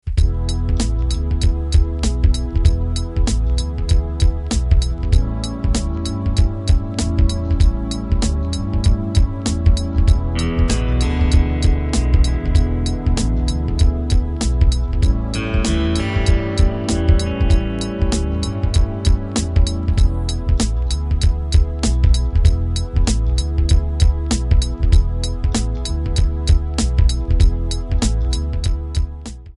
MPEG 1 Layer 3 (Stereo)
Backing track Karaoke
Pop, 1990s